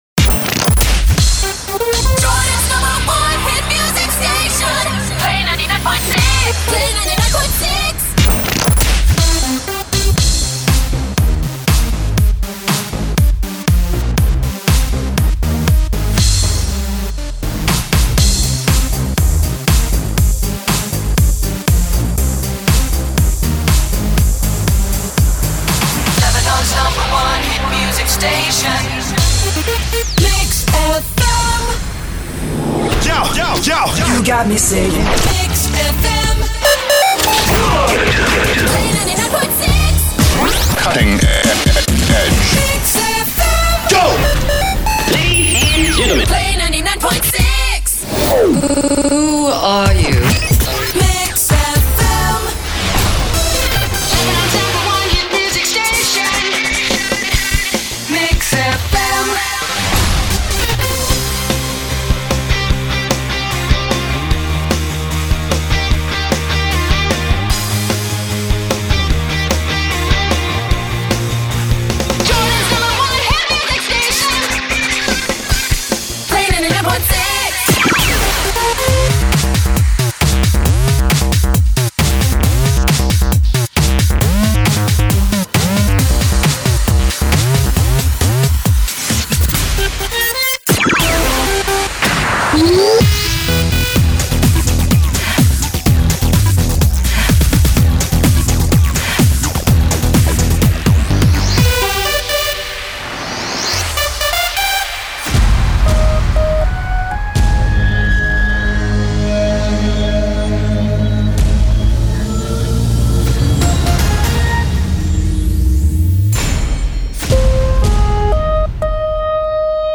– Sweep ID 61, 62, 63, 64, 65